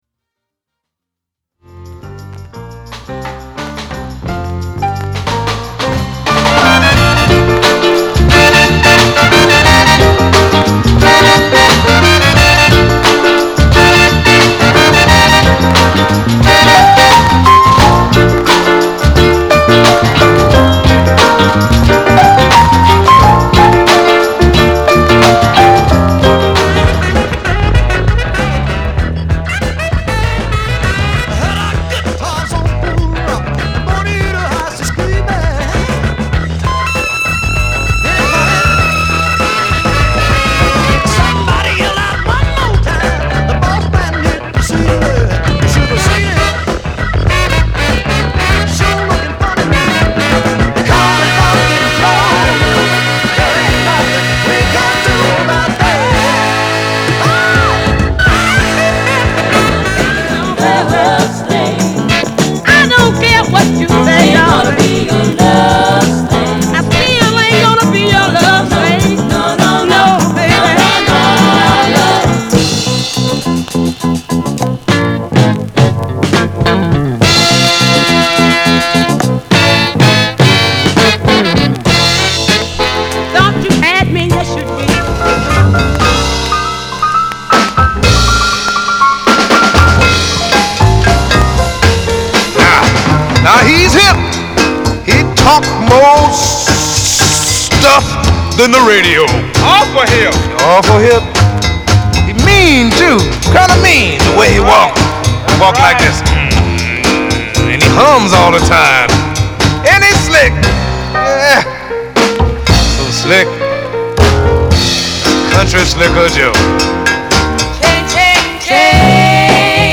R&B、ソウル
/盤質/両面目立った傷なし/US PRESS